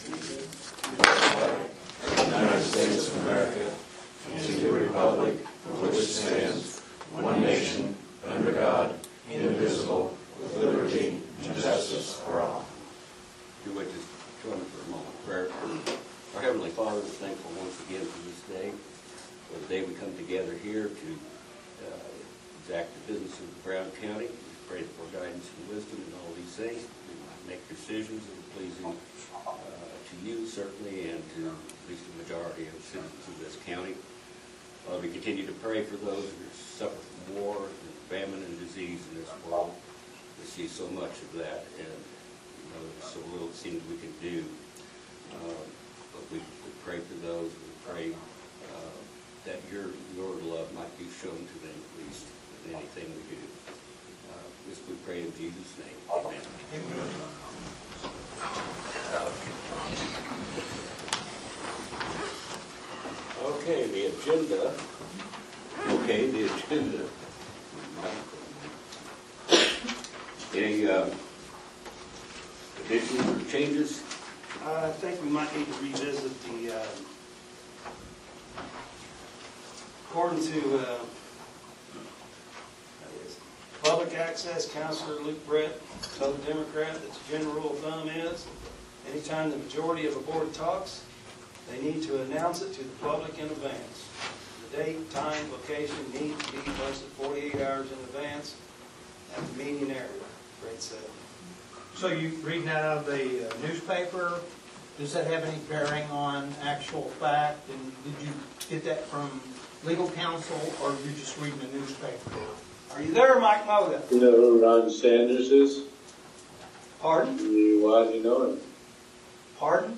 Commissioner Meeting Notes, May 15, 2024 Audio of the Meeting AGENDA Commissioner Meeting This post at Brown County Matters.